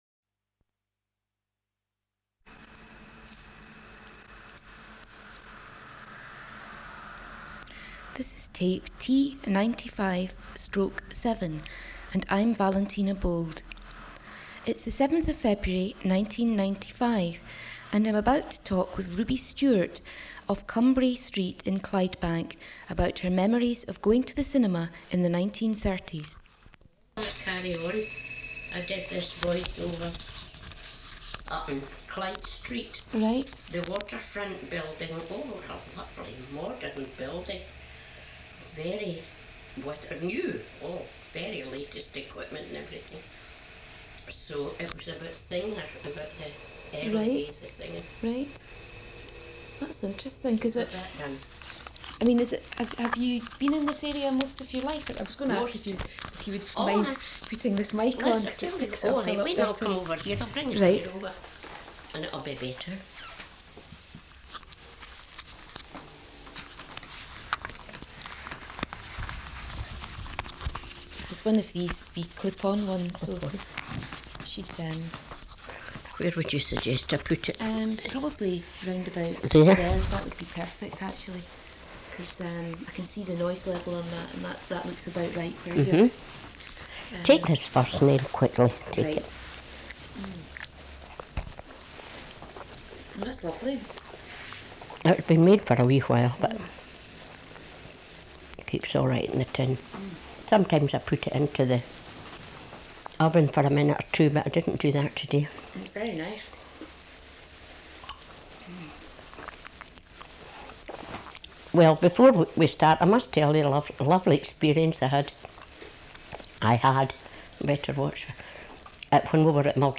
Interview audio in new tab